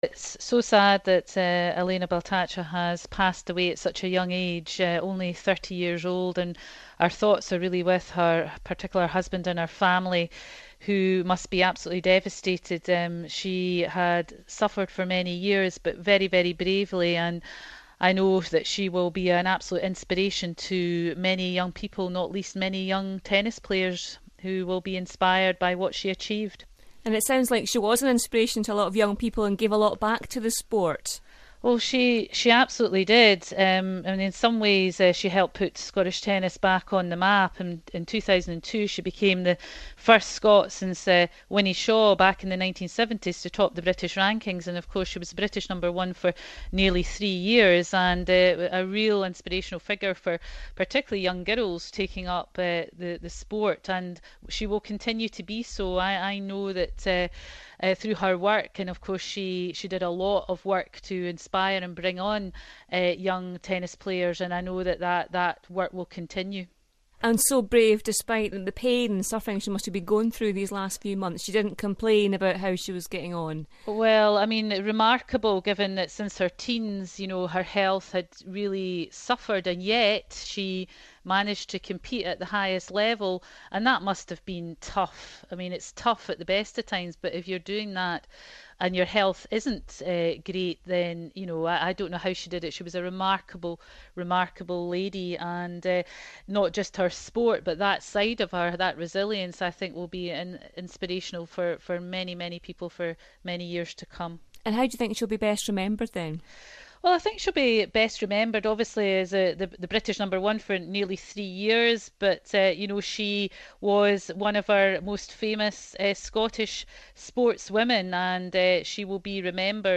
Scotland's sports minister, Shona Robison, speaks to Northsound's